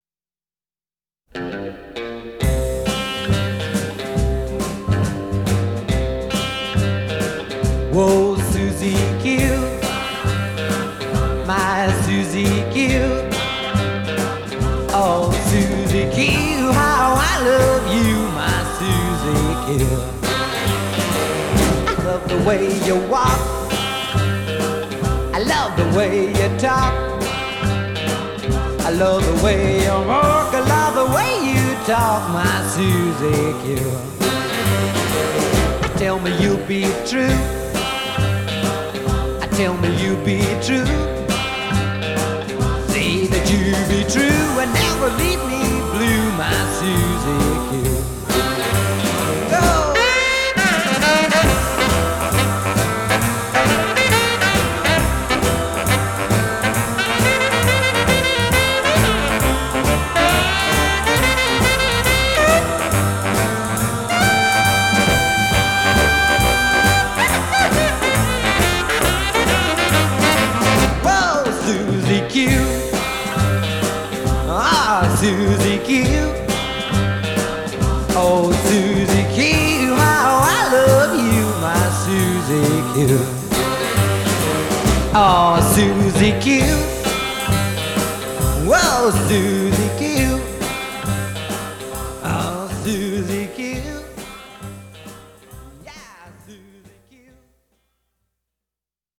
Genre: Pop, Rock & Roll, Beat